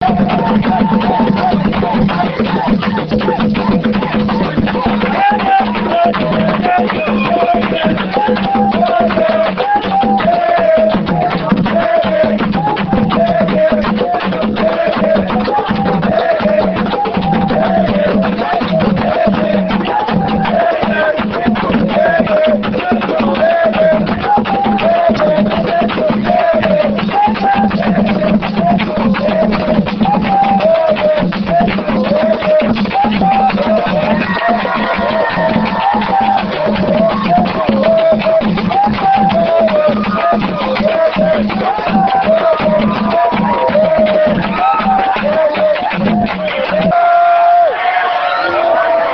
enregistrement durant une levée de deuil (Puubaaka)
danse : songe (aluku) ; levée de deuil
Genre songe
Pièce musicale inédite